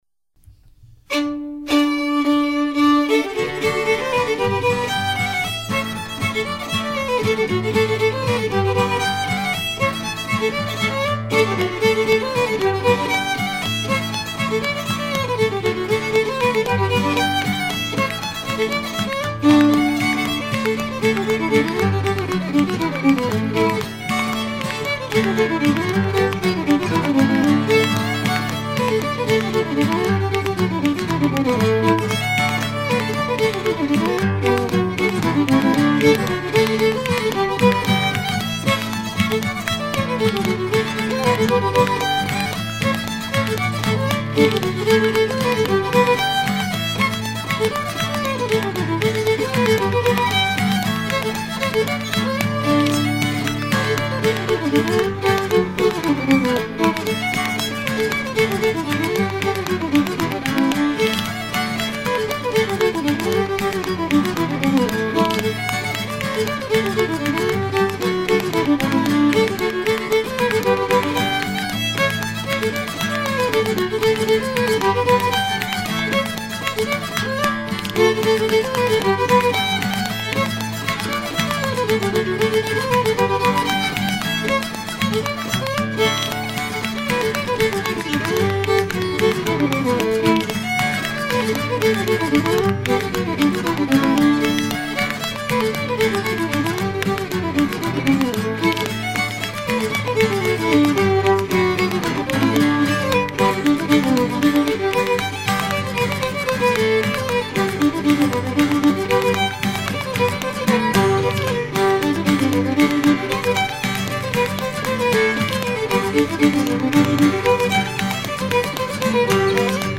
danse : reel ; danse : hornpipe ;
Pièce musicale éditée